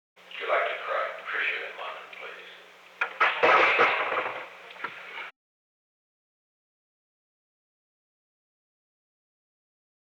The Oval Office taping system captured this recording, which is known as Conversation 823-004 of the White House Tapes.
Location: Oval Office
The President talked with the White House operator.